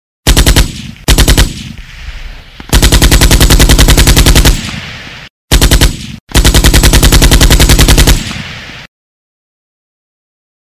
Pulemet.mp3